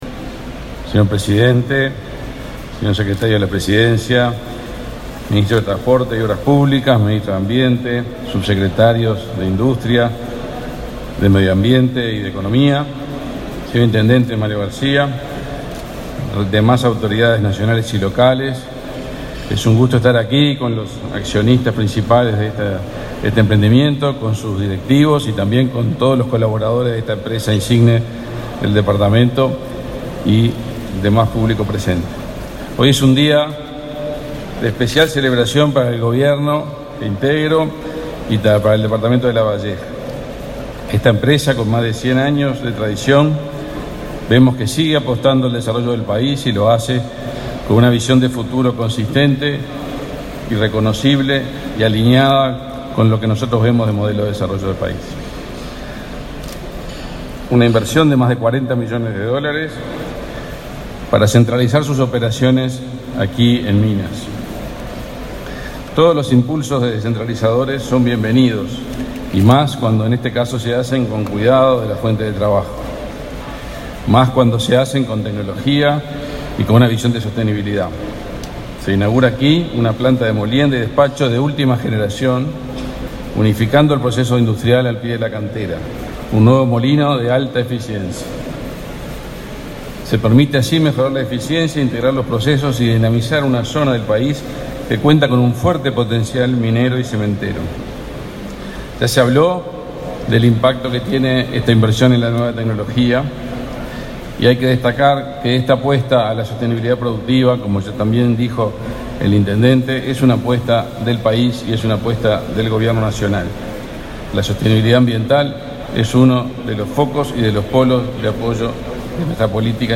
Palabras del ministro de Industria, Omar Paganini
El ministro de Industria, Omar Paganini, participó este jueves 19 en la inauguración de una planta de molienda y despacho, de última generación, de